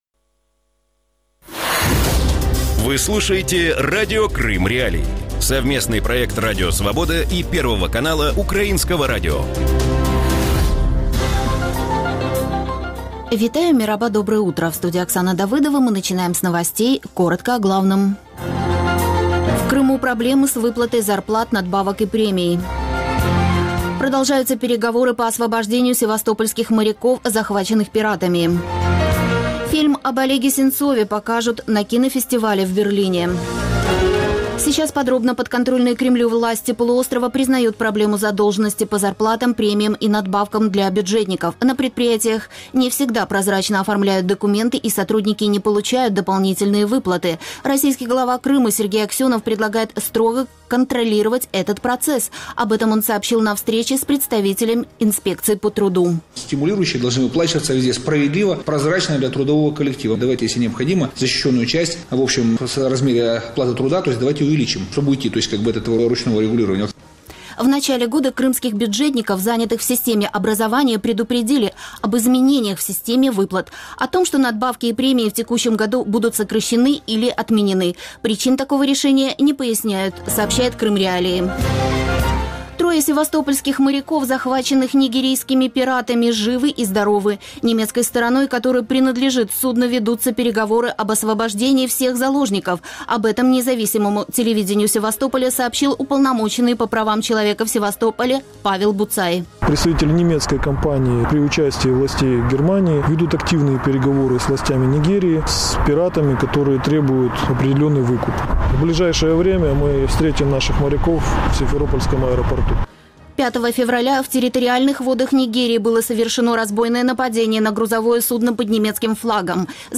Утренний выпуск новостей о событиях в Крыму. Все самое важное, что случилось к этому часу на полуострове.